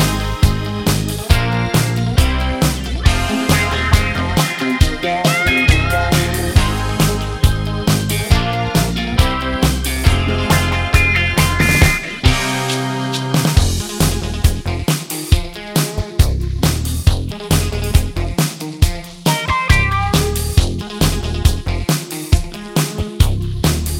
For Solo Singer Pop (1980s) 4:47 Buy £1.50